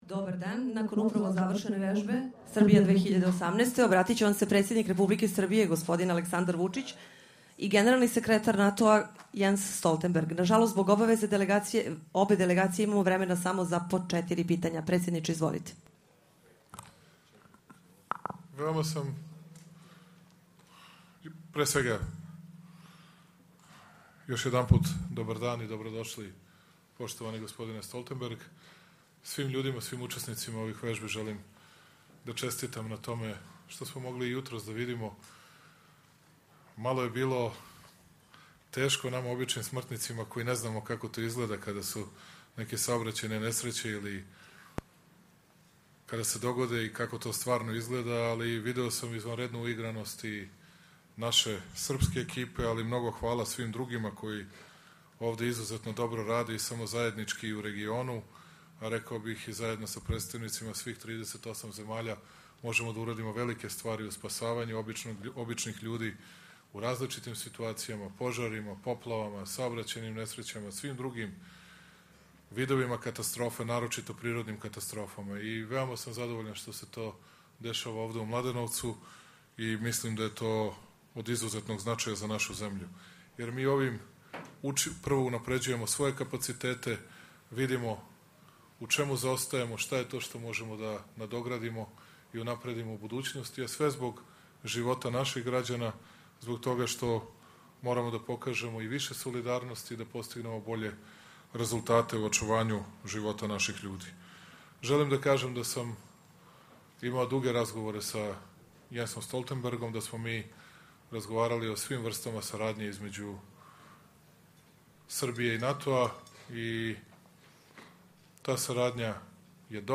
Joint press conference
with NATO Secretary General Jens Stoltenberg and the President of the Republic of Serbia, Aleksandar Vucić